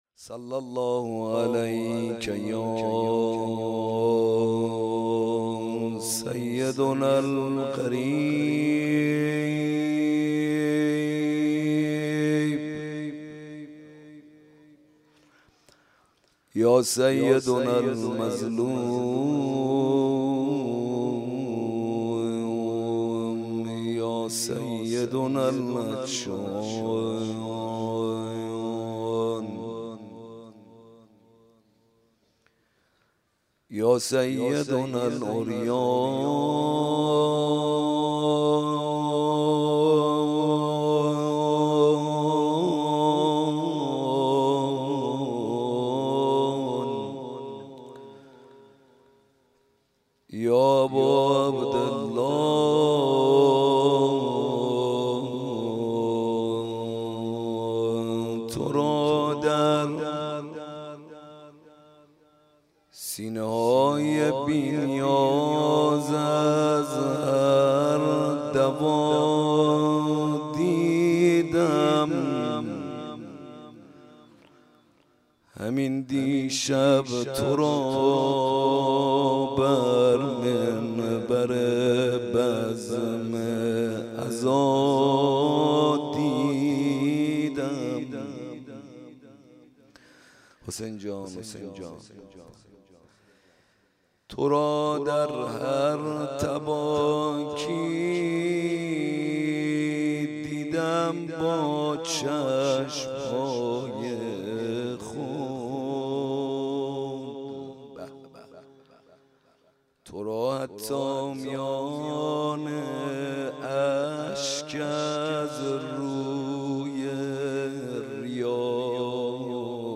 حسینیه کربلا